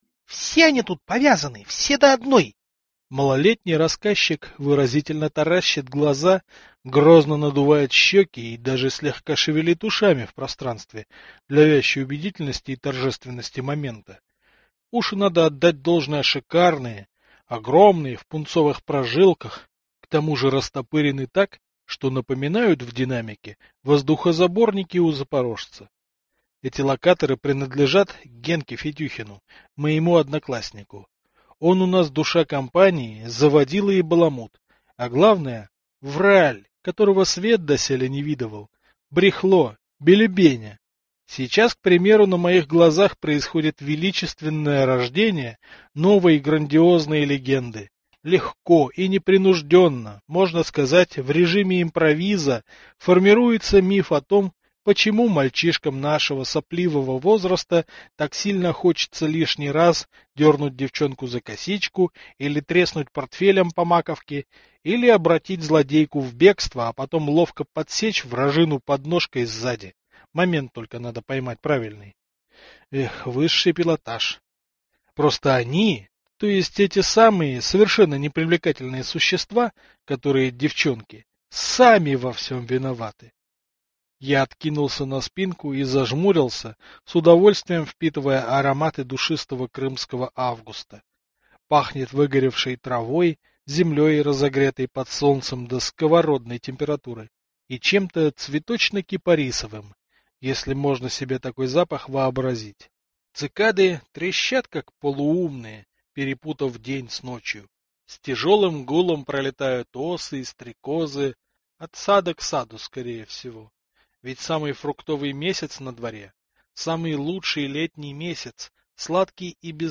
Аудиокнига Фатальное колесо. Третий не лишний | Библиотека аудиокниг